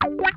CRUNCHWAH 14.wav